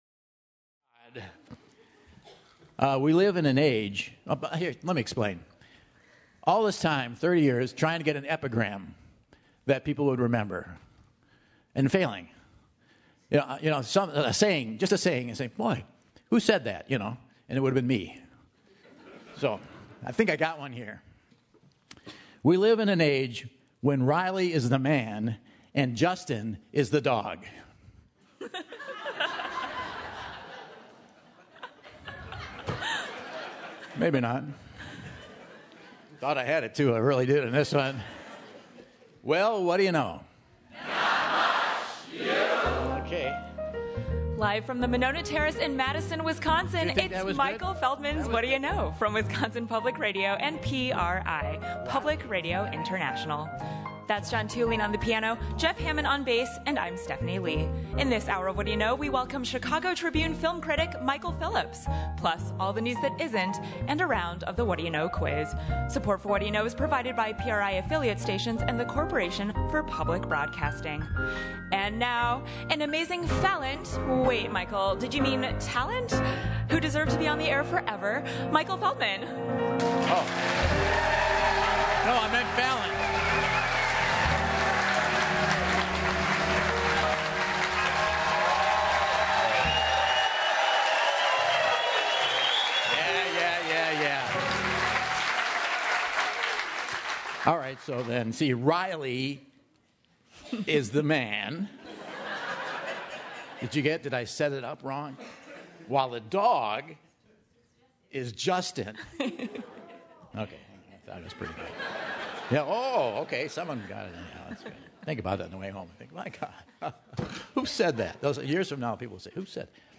June 4, 2016 - Madison, WI - Monona Terrace | Whad'ya Know?